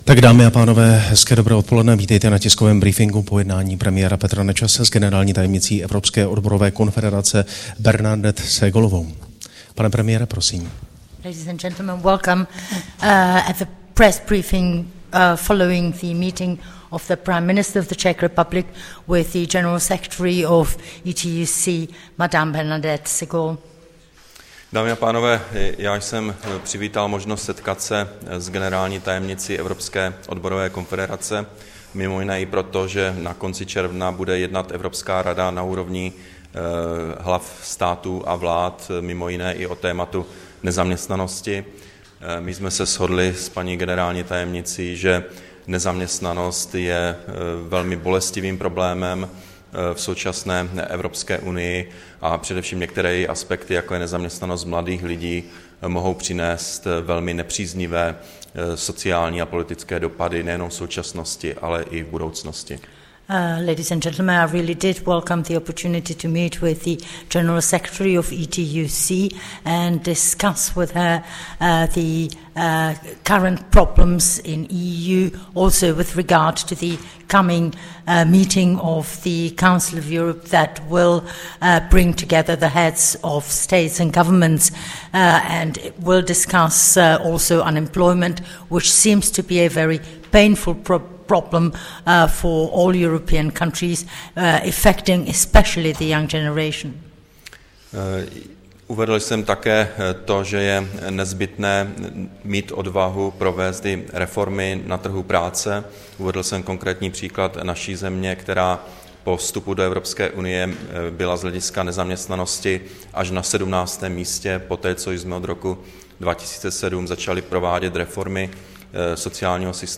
Tiskový brífink po jednání premiéra Petra Nečase